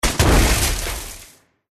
GameMpassetsMinigamesCjsnowEn_USDeploySoundGameplaySfx_mg_2013_cjsnow_impactpowercardsnow.mp3